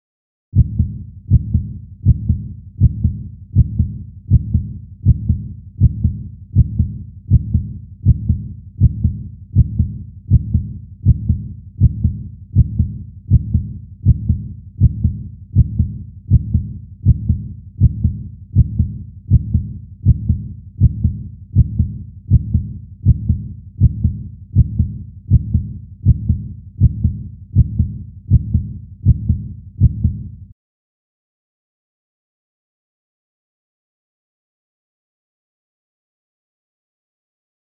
Des bruits de machinerie, de pics et de pelles, rappellent aussi l’ambiance sonore de ces environnements parfois dangereux, où sont employées de nombreuses personnes que nous pouvons imaginer dans l’écho d’un cœur qui bat.
The sounds of machinery, of picks and shovels evoke the ambience of these sometimes dangerous environments, while the echo of a beating heart suggests the human being at work.
coeur 80 bpm.mp3